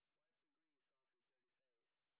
sp05_white_snr30.wav